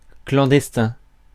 Ääntäminen
IPA : /klænˈdɛstɪn/